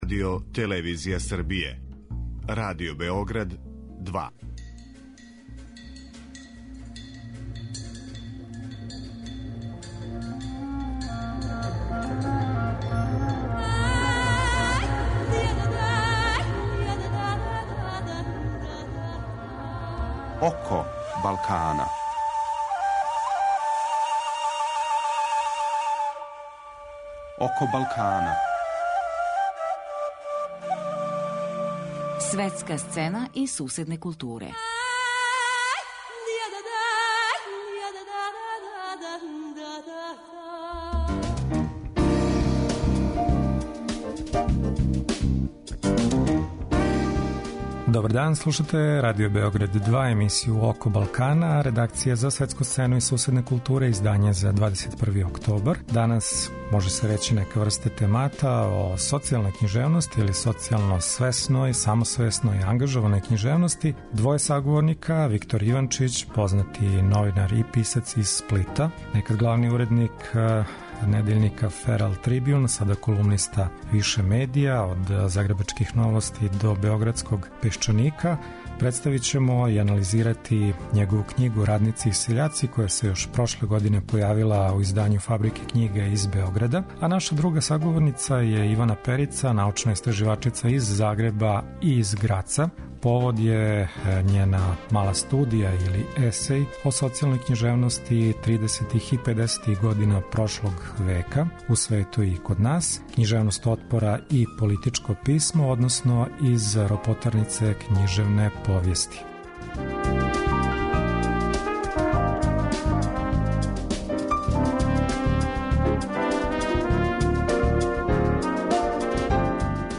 Повод за разговор је њен есеј о књижевности отпора и политичком писму .